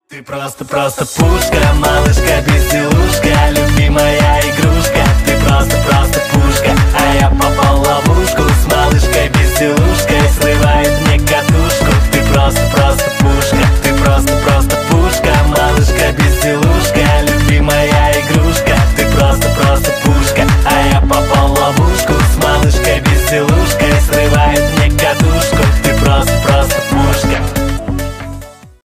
• Качество: 320 kbps, Stereo
Поп Музыка
весёлые